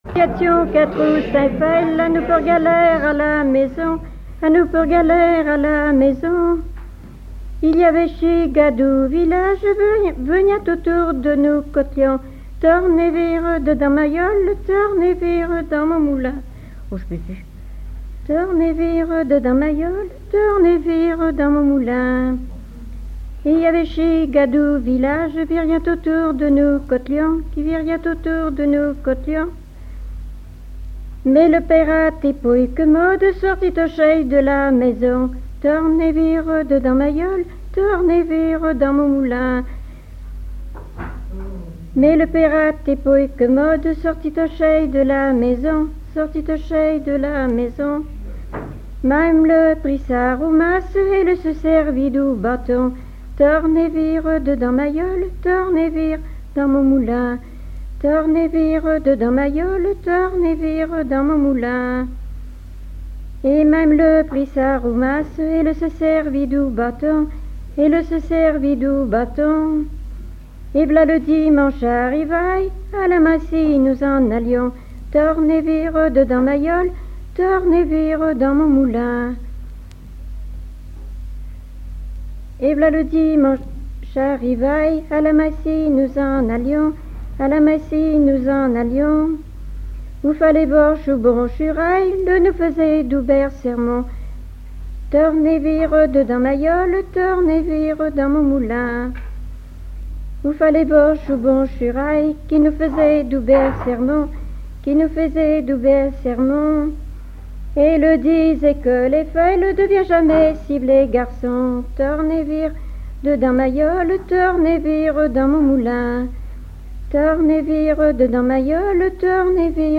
Mémoires et Patrimoines vivants - RaddO est une base de données d'archives iconographiques et sonores.
à la salle d'Orouët
Pièce musicale inédite